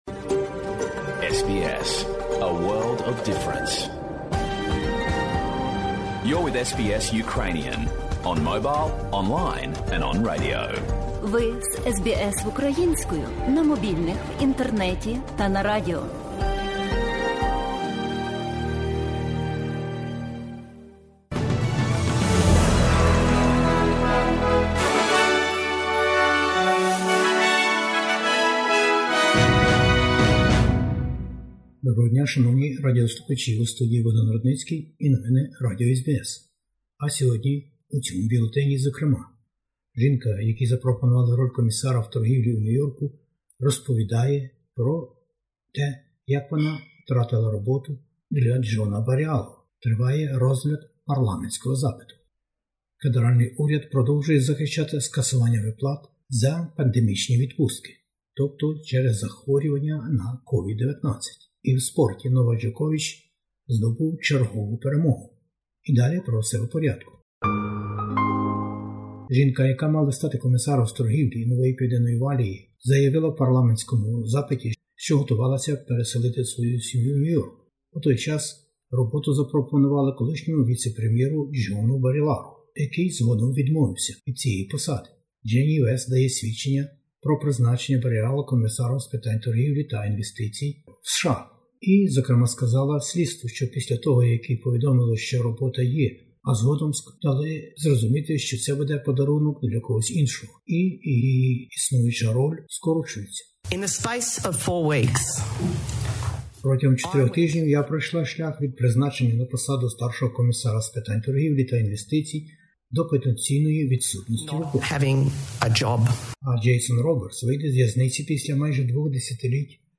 Бюлетень SBS новин українською мовою. COVID-19 - зміни в оплаті для тих, котрі не мають оплачуваної відпустки, ізоляція, щеплення 4-ої дози вакцин, антивірусні препарати для тих, кому за 70 літ. Повені у НПВ і допомогові виплати Федерального уряду.